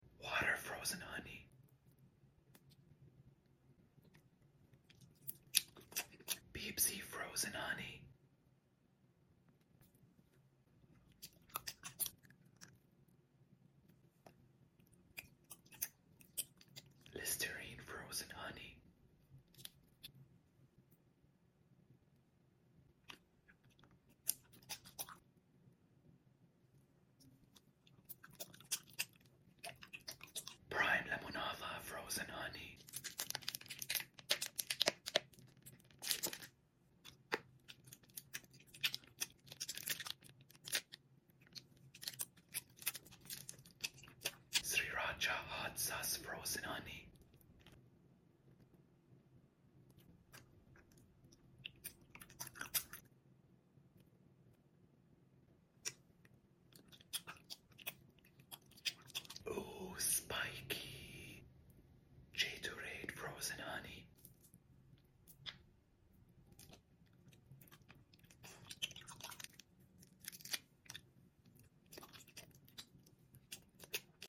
Food ASMR Eating Frozen Honey Sound Effects Free Download